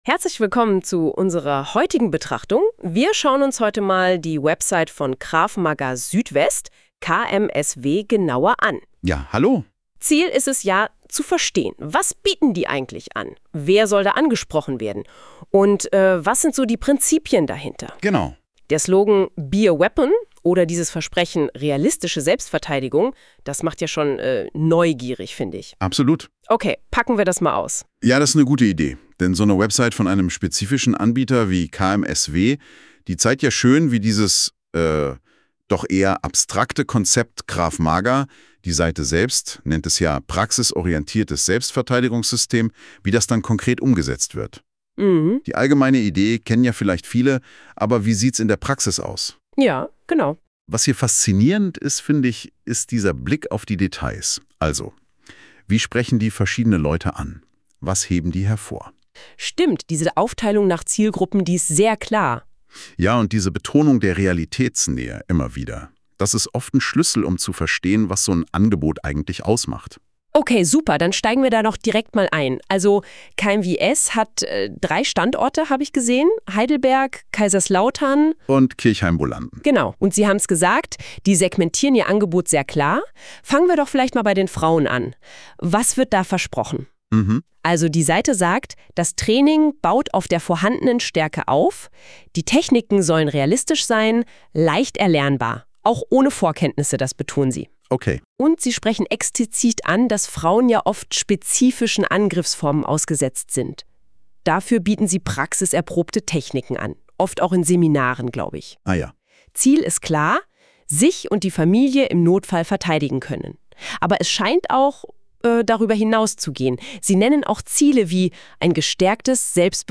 Hinweis: AI generiert
Ich habe eine KI gebeten, ein Video und einen Podcast über uns zu generieren.